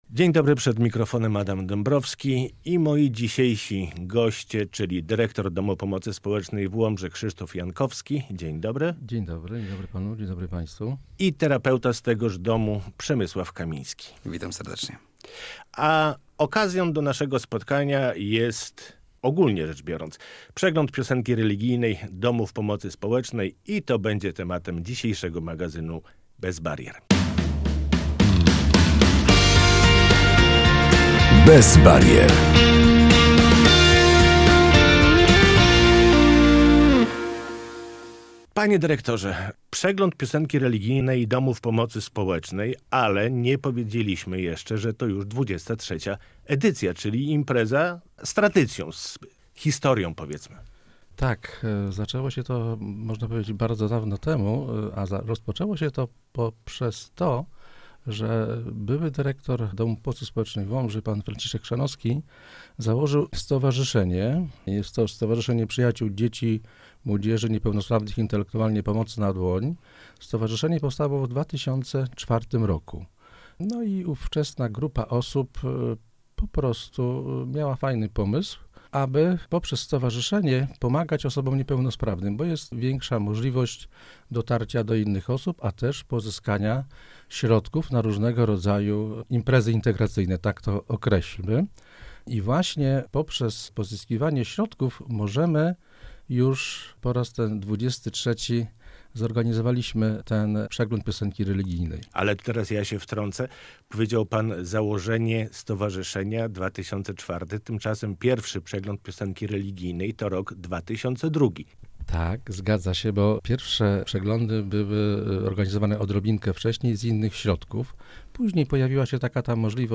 Przegląd Piosenki Religijnej Domów Pomocy Społecznej